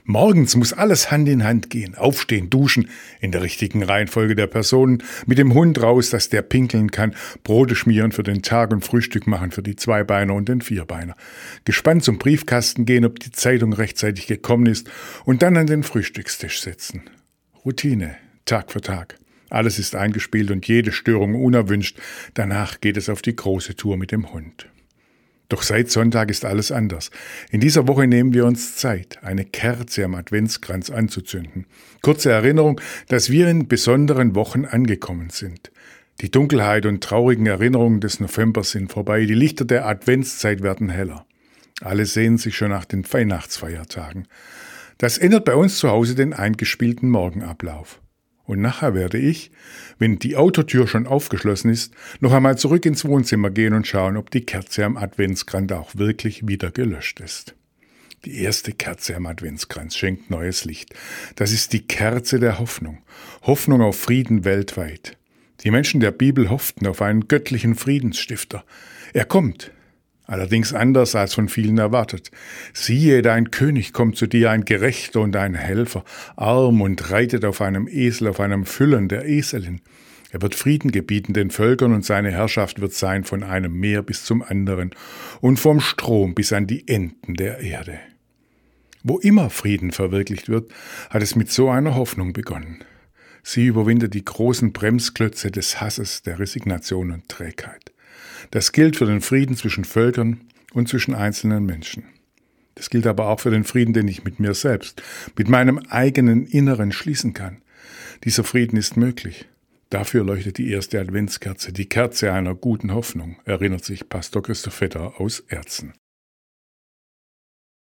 Radioandacht vom 2. Dezember